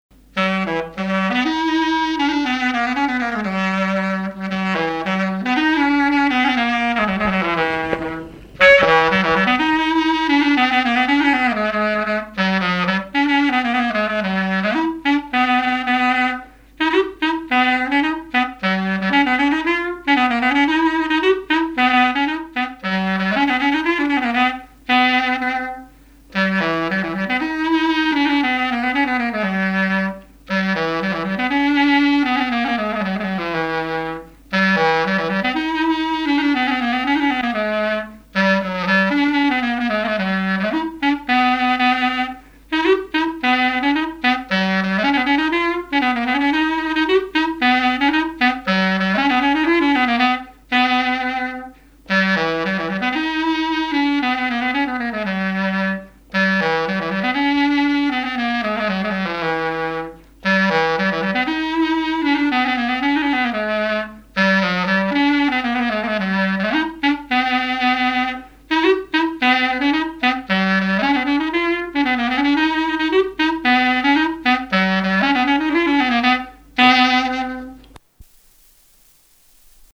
Fonction d'après l'analyste gestuel : à marcher
circonstance : fiançaille, noce
Pièce musicale inédite